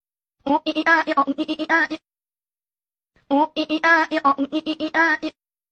oiia-oiia-sound.wav